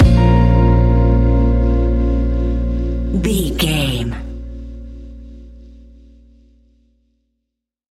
Ionian/Major
A♯
laid back
Lounge
sparse
new age
chilled electronica
ambient